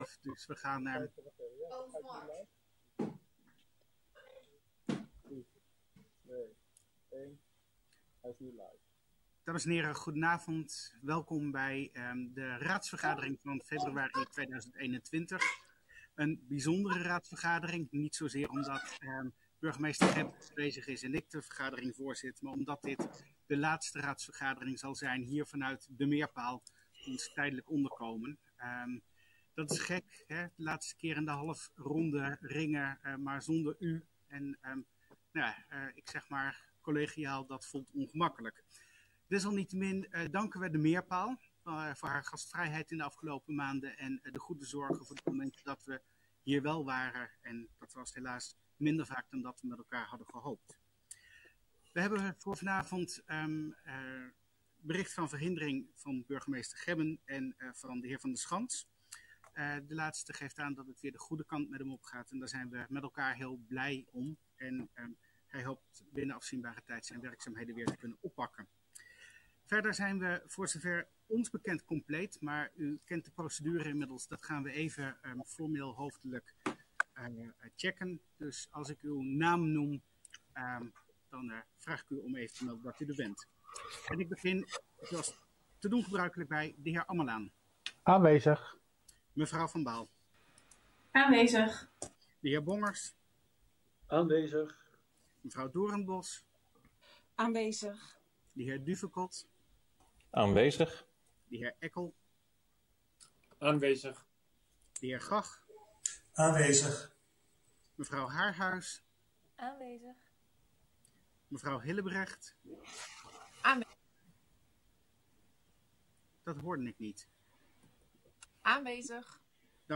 Deze vergadering wordt digitaal gehouden en is hieronder via de live stream te volgen.